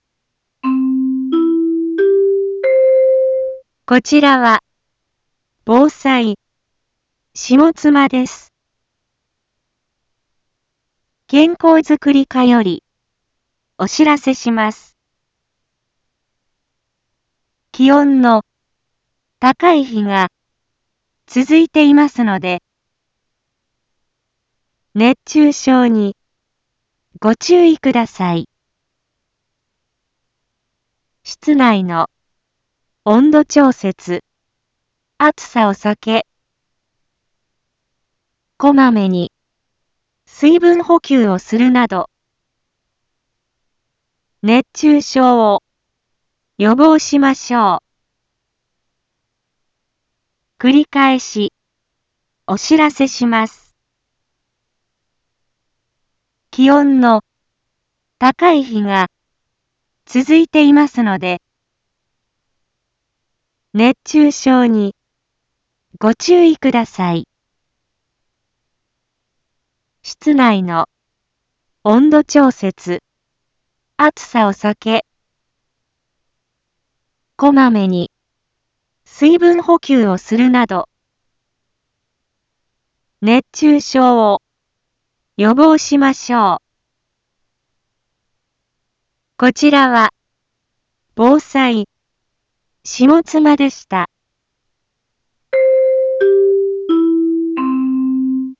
一般放送情報
Back Home 一般放送情報 音声放送 再生 一般放送情報 登録日時：2023-07-27 11:01:42 タイトル：熱中症注意のお知らせ インフォメーション：こちらは、防災、下妻です。